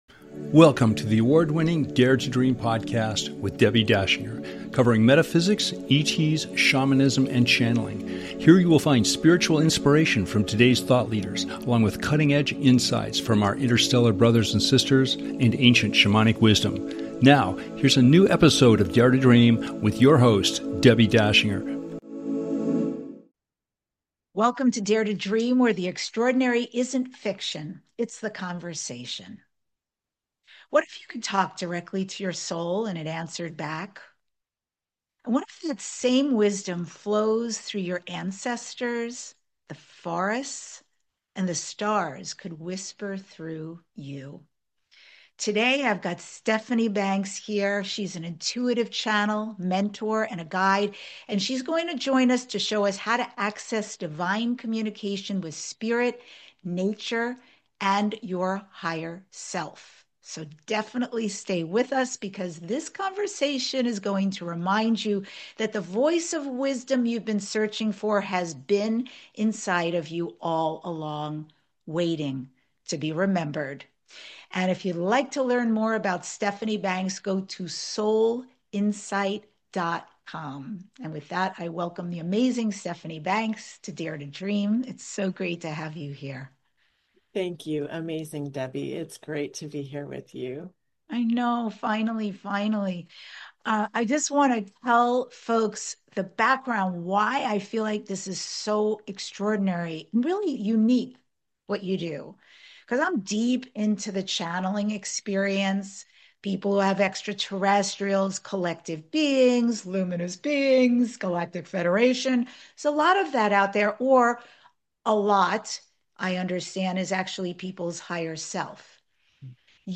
You’ll hear a live channeled transmission, surprising truths about parenting through intuition, and the real reason you’re already channeling without even realizing it. If you’ve ever wondered whether Spirit is speaking to you… this episode proves it.